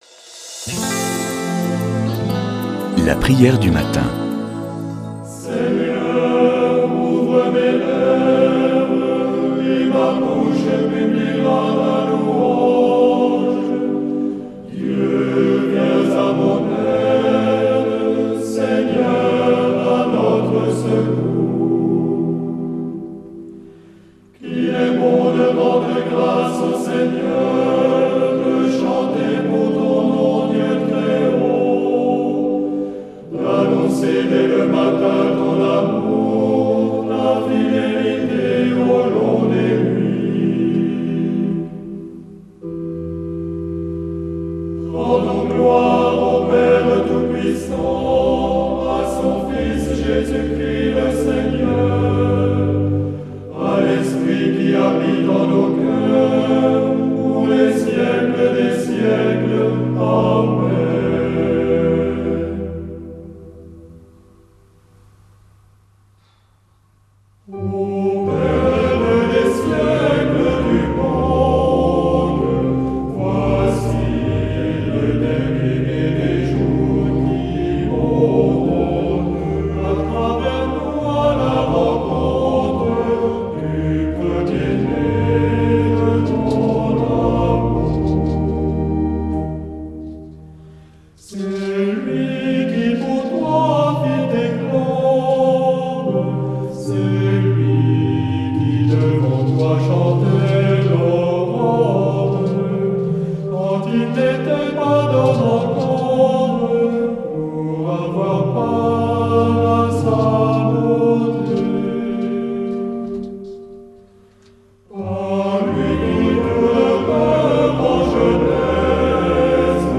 Prière du matin
ABBAYE DE LA PIERRE QUI VIRE